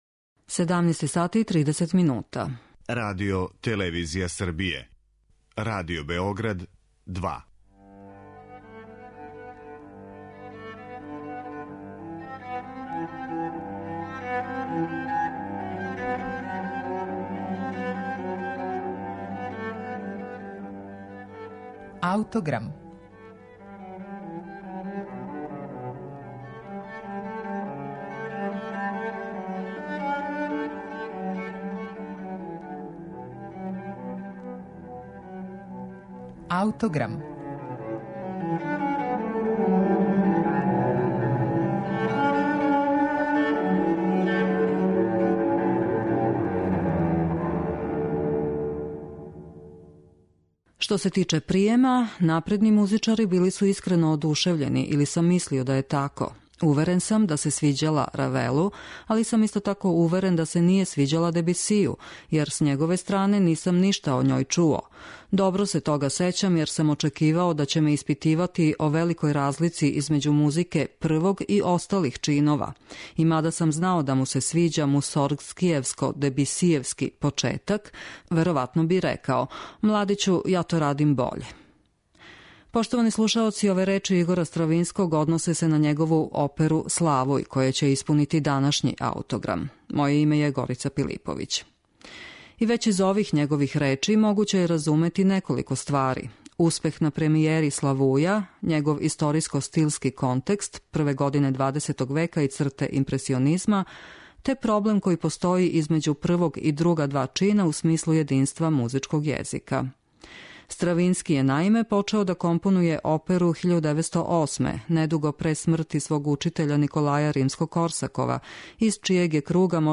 Дело припада такозваном жанру опере-бајке, а либрето је рађен према тексту Ханса Кристијана Андерсена.